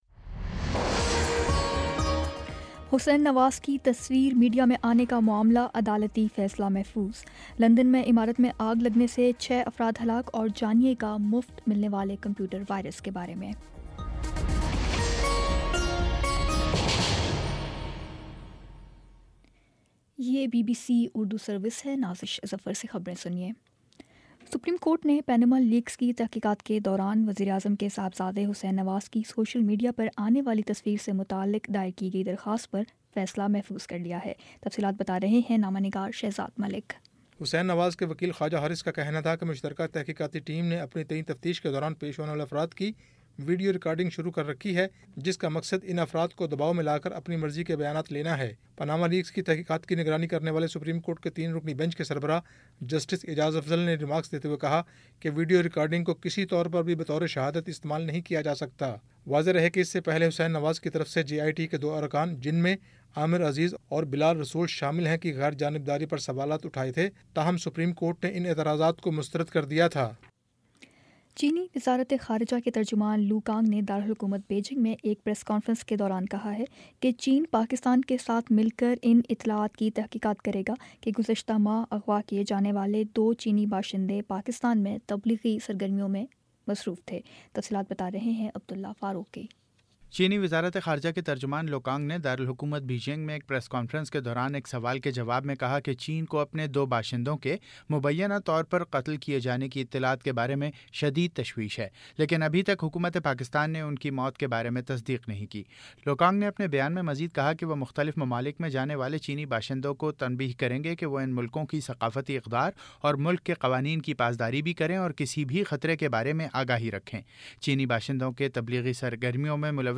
جون 14 : شام چھ بجے کا نیوز بُلیٹن